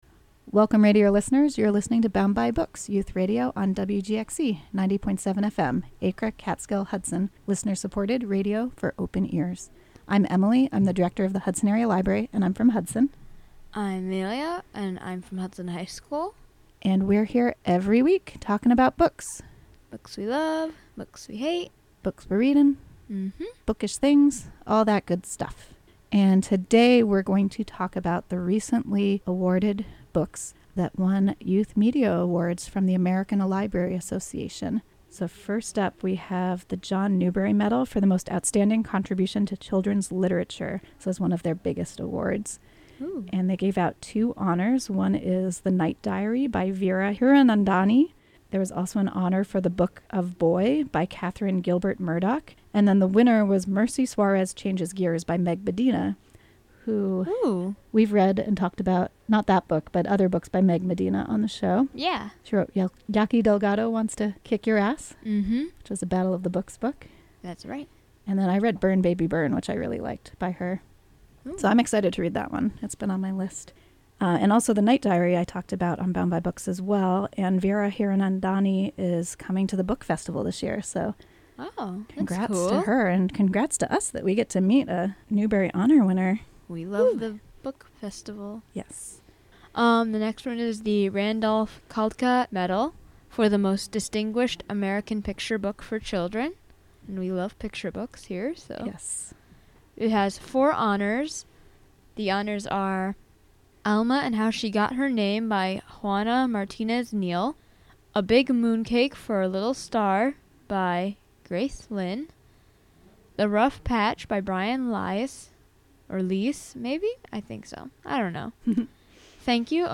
Recorded at the WGXC Hudson Studio, Mon, Jan. 28.